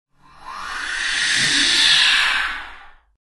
Звуки вампиров
Здесь собраны самые жуткие и реалистичные аудиоэффекты: втягивание крови, трансформирующиеся тела, шаги в темноте и загадочный смех.